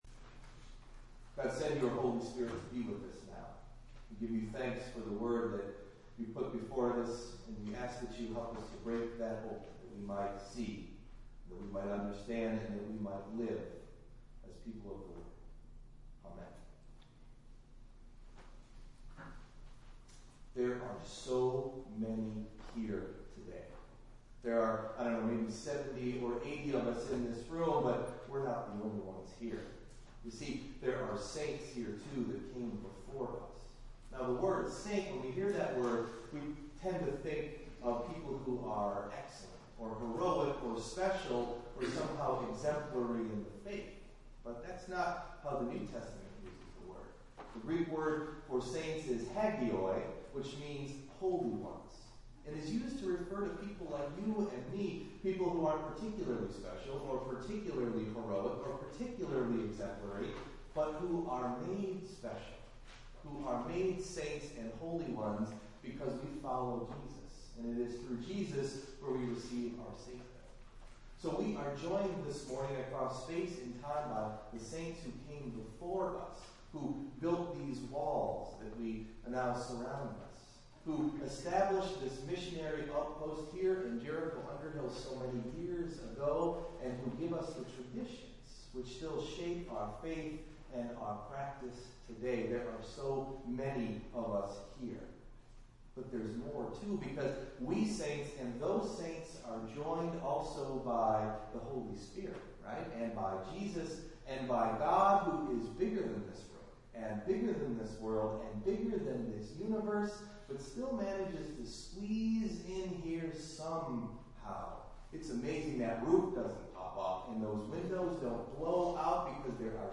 Delivered at: The United Church of Underhill
This week you will hear a short meditation which served in our Sunday morning worship servivce as an introduction to the Naming of the Saints which followed.